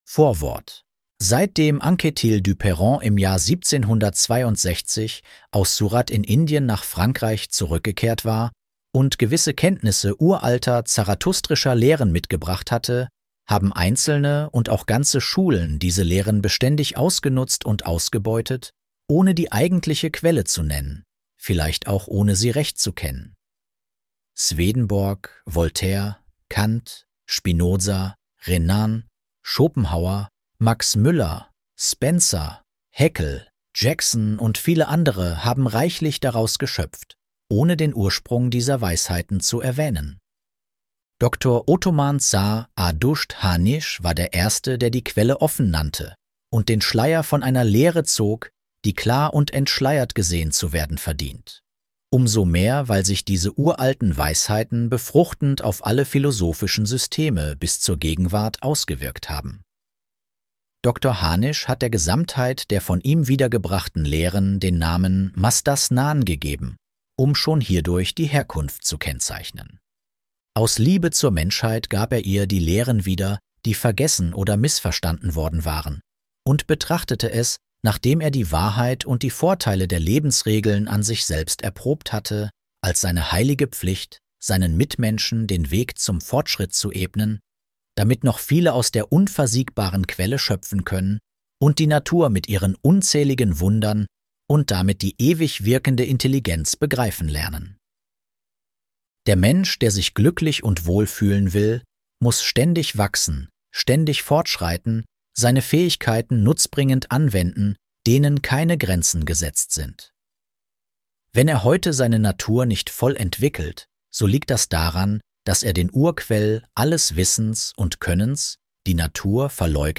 Atem- und Gesundheitskunde - Hörbuch